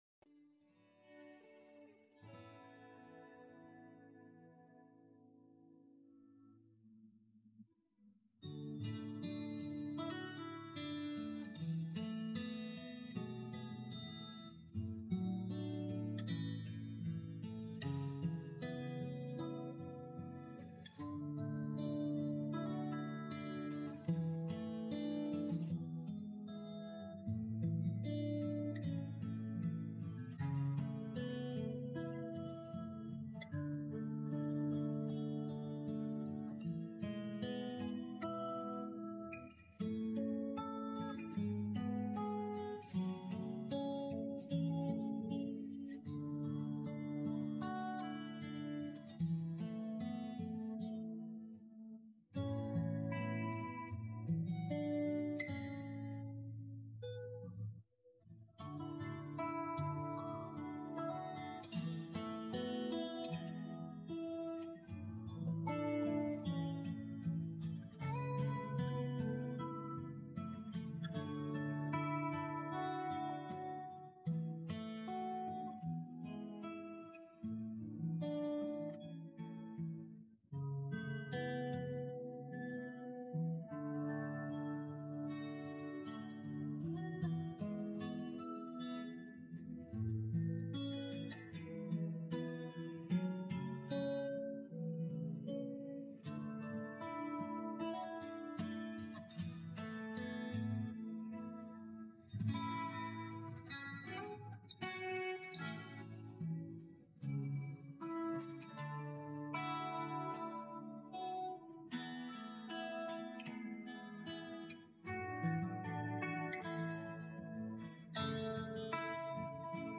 Guitar track